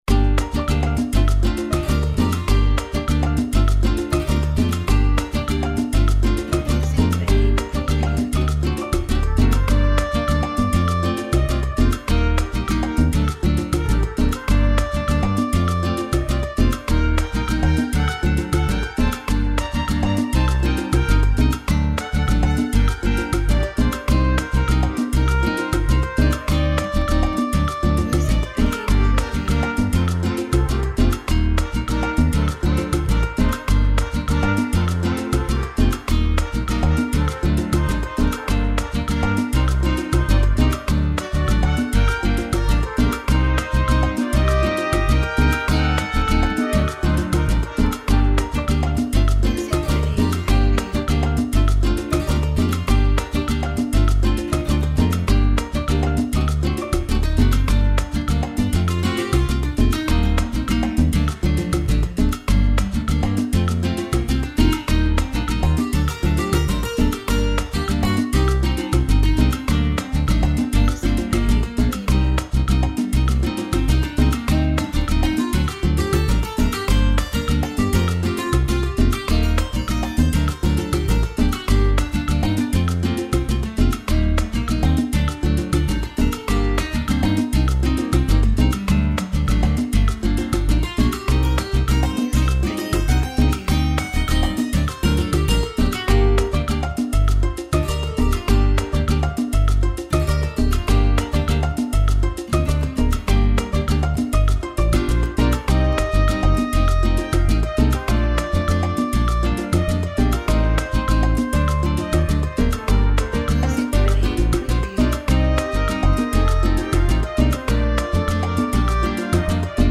Tempo (BPM): 105